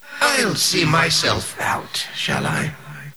Worms speechbanks
Takecover.wav